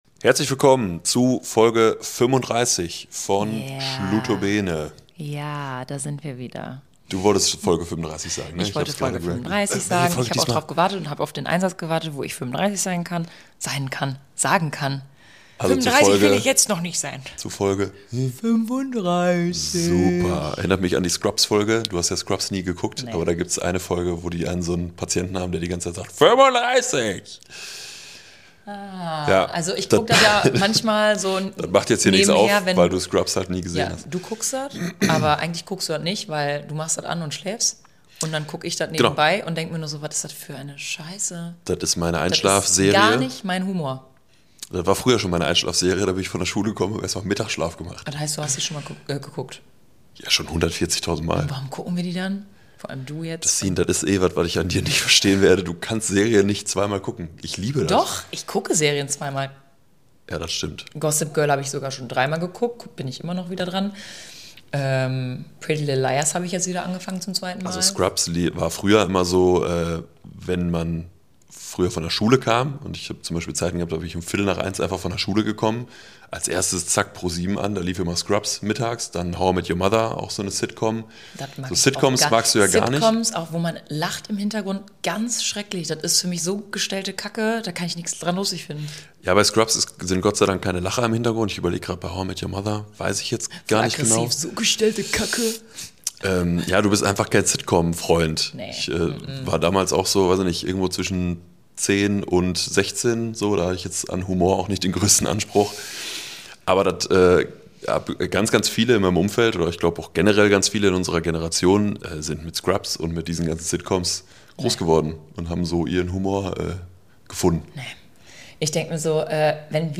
Ein Talk aus dem Nähkästchen des Herzens!